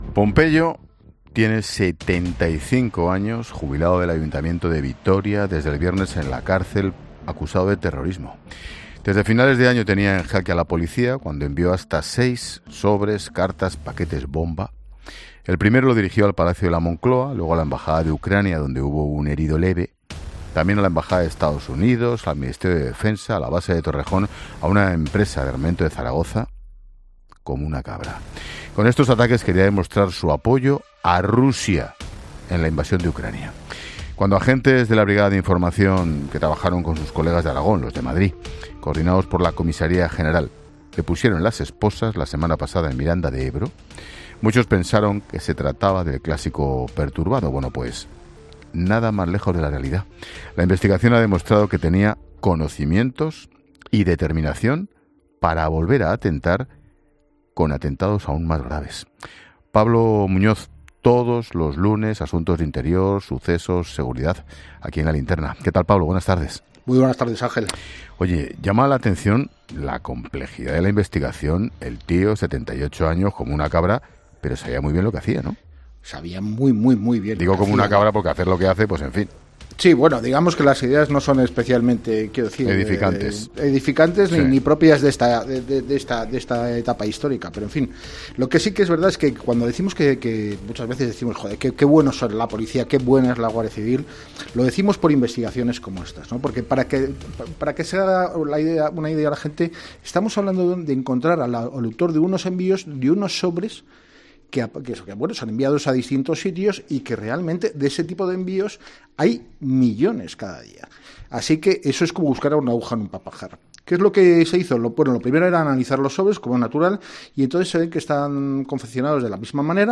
periodista de ABC, explica en ‘La Linterna’ de COPE la complejidad de la investigación